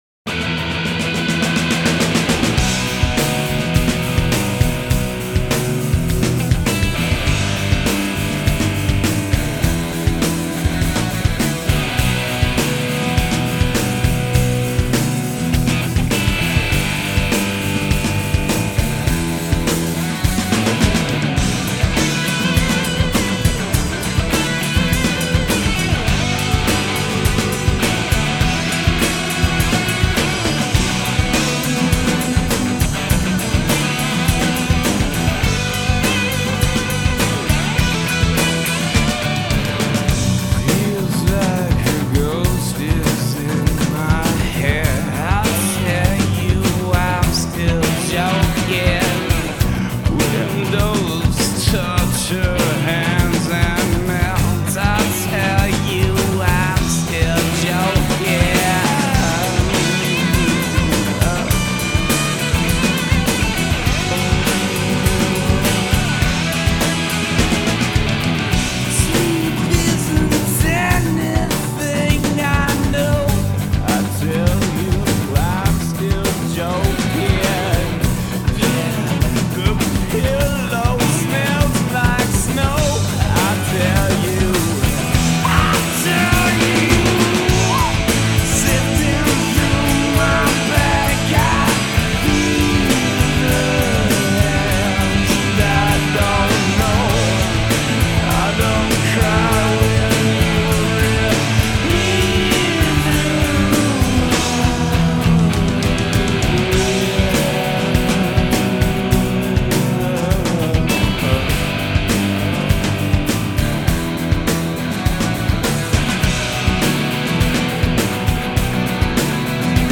LIVE IN 09!